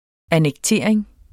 Udtale [ anεgˈteˀɐ̯eŋ ]